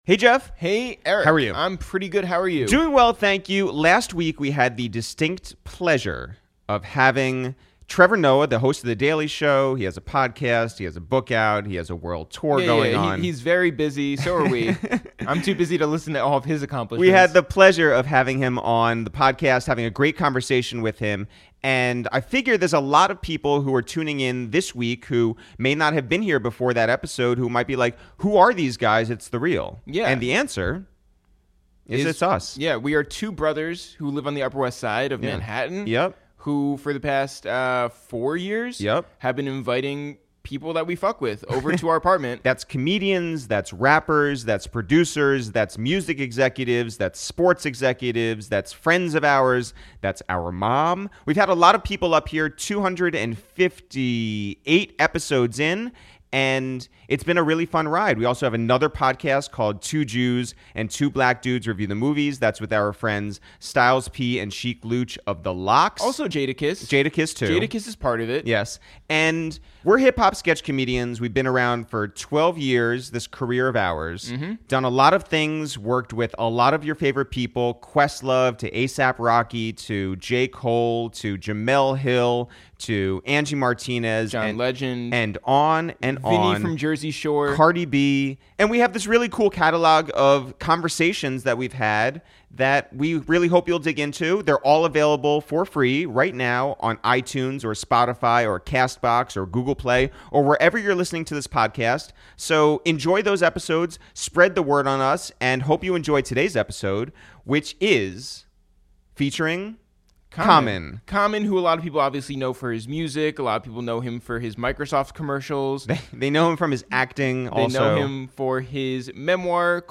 This week on A Waste of Time with ItsTheReal, we travelled to Los Angeles and sat down with Common for a deep, hilarious and very meaningful conversation.